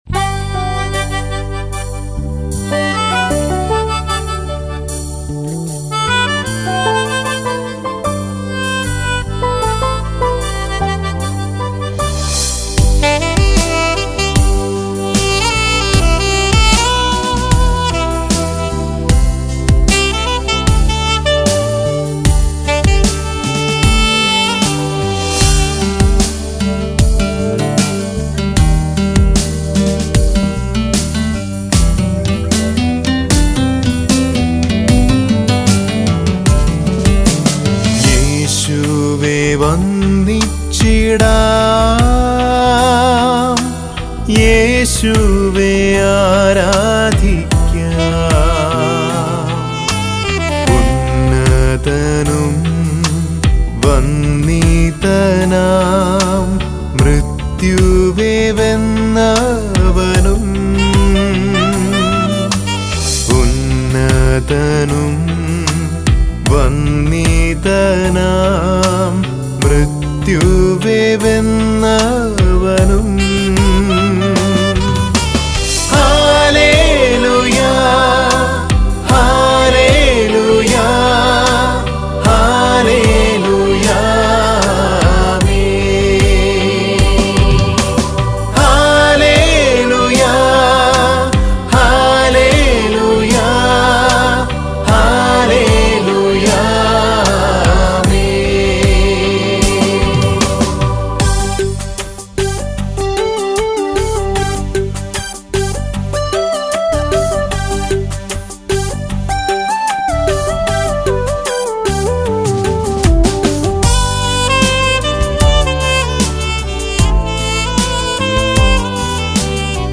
Christian Devotional Songs & Video Albums